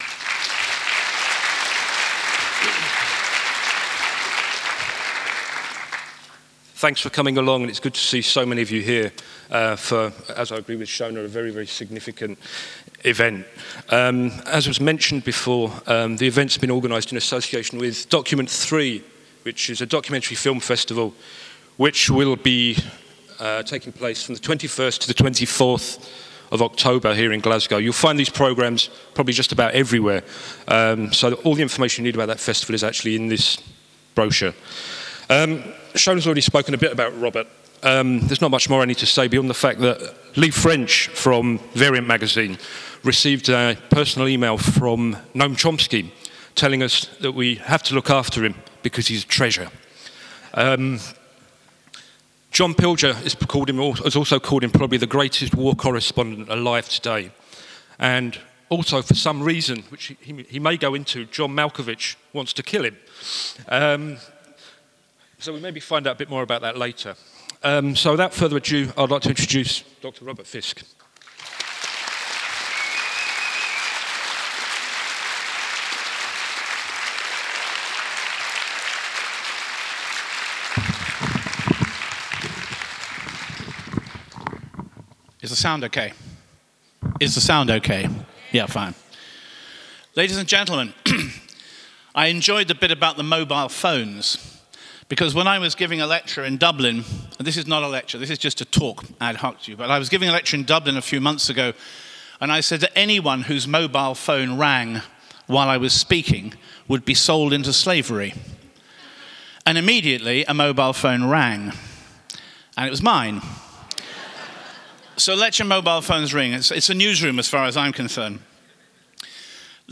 Document 3 : International Human Rights Documentary Film Festival inaugural public talk, in collaboration with Glasgow School of Art, by Robert Fisk on his new book 'The Great War for Civilisation : The Conquest of the Middle East'.